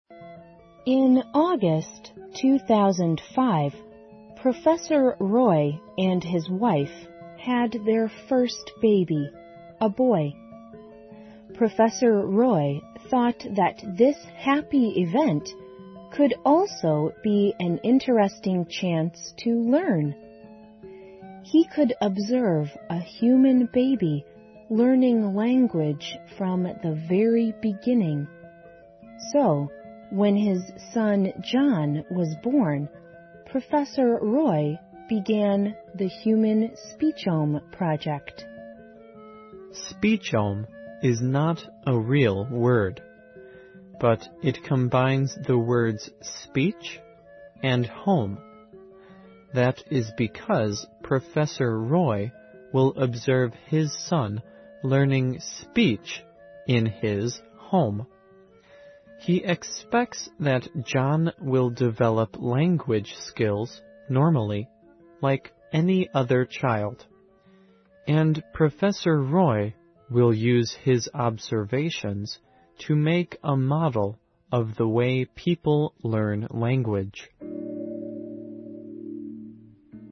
环球慢速英语 第552期:人类家庭语言计划(3)